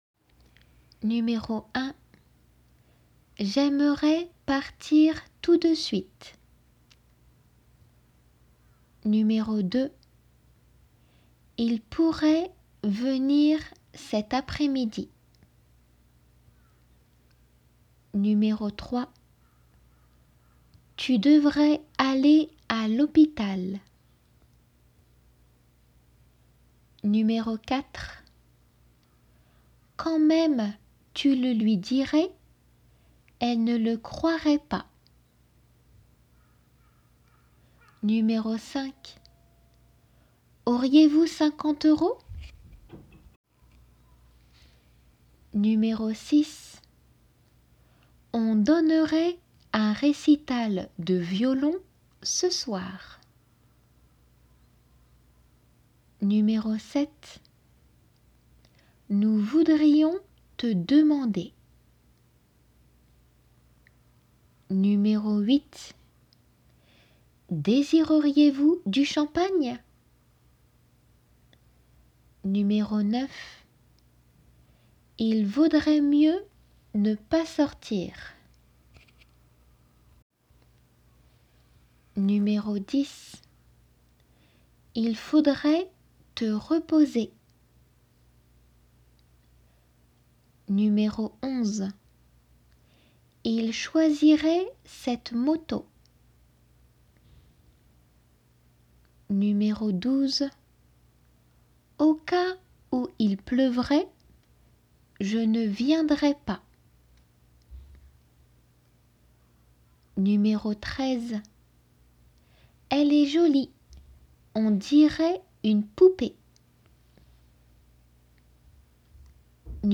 音声には解答の語が入って居ます。
練習用です。